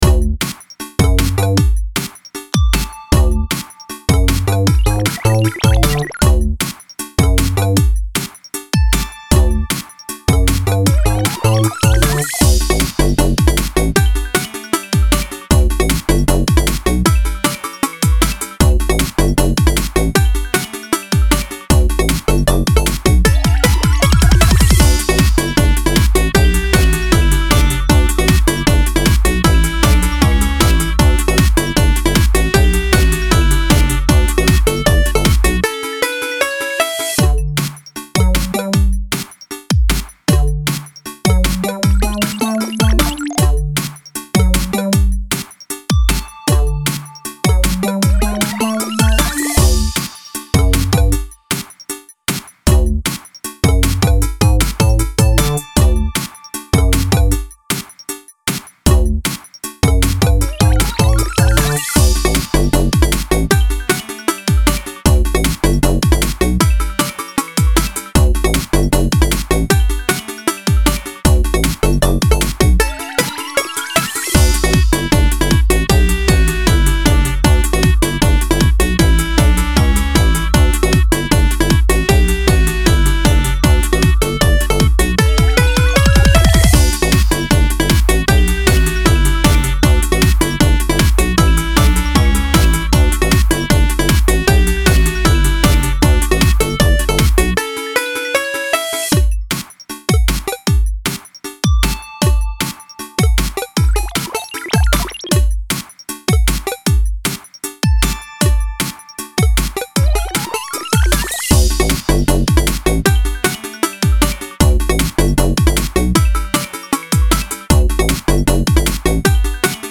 Loop.mp3